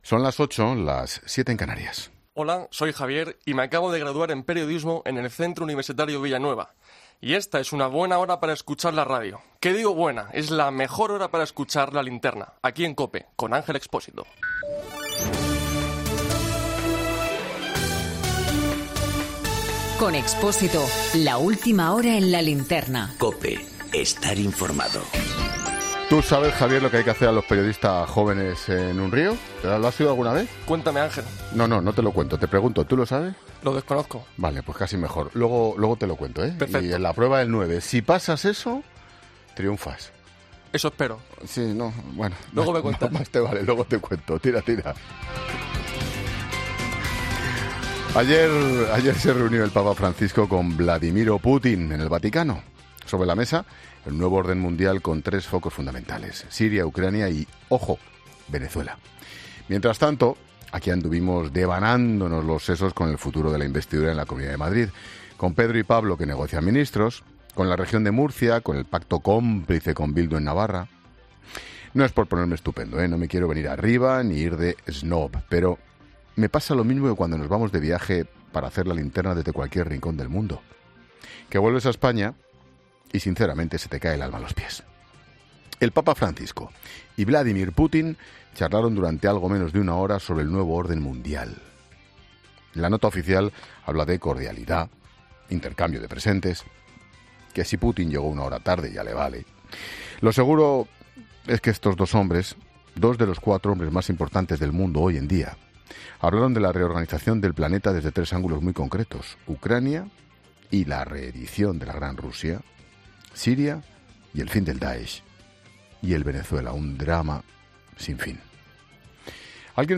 AUDIO: El análisis de la jornada en el monólogo de Ángel Expósito en La Linterna